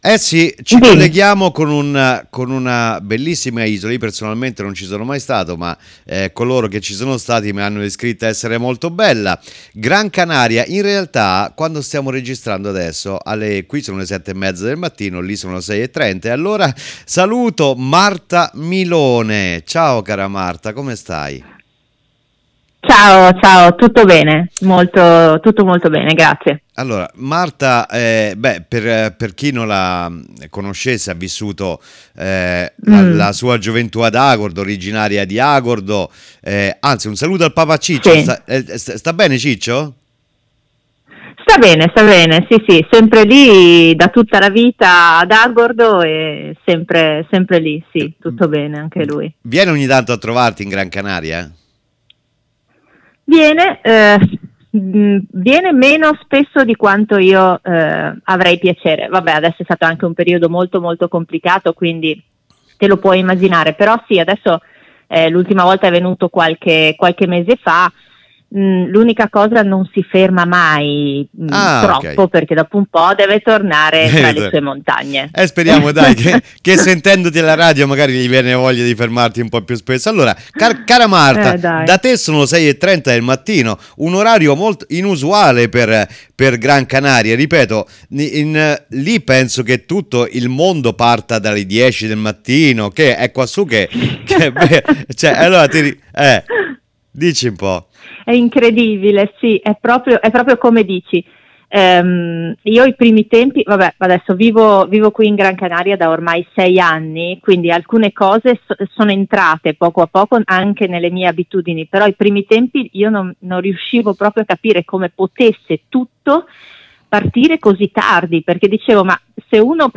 IERI ALLA RADIO
ZERO TERMICO E’ UN VIAGGIO ALLA SCOPERTA DEL NOSTRO TERRITORIO, DALLA VOCE DEI PROTAGONISTI. TURISMO, SPORT, EVENTI, CULTURA, INTRATTENIMENTO.